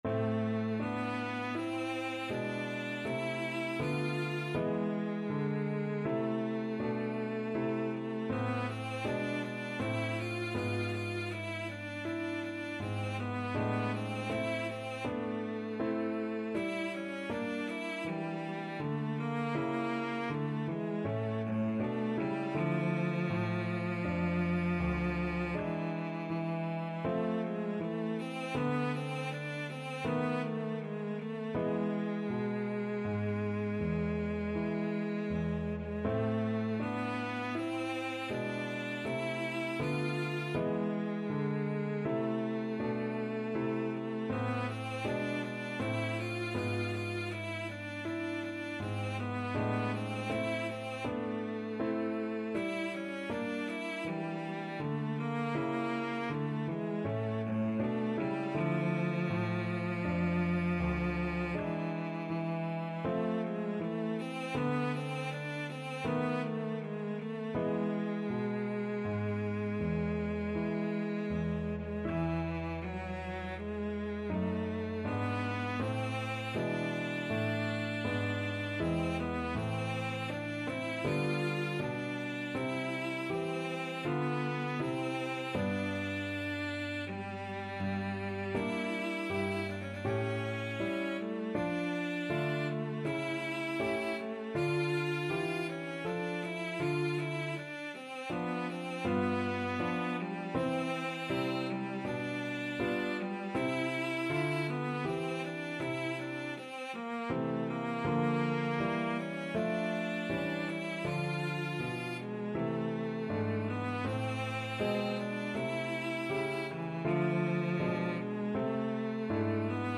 Free Sheet music for Cello
Cello
A minor (Sounding Pitch) (View more A minor Music for Cello )
3/2 (View more 3/2 Music)
III: Largo =40
Classical (View more Classical Cello Music)